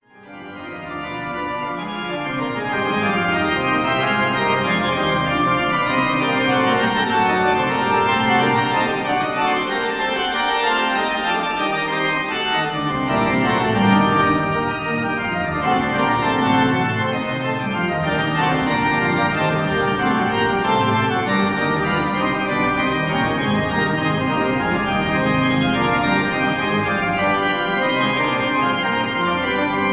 fugue in c minor for organ
Austin Organ 4/68 First Presbyterian Church of Orange